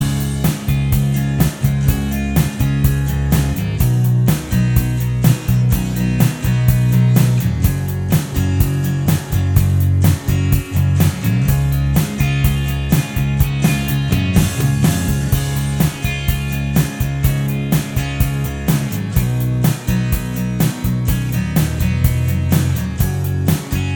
Minus Lead Guitar Rock 5:53 Buy £1.50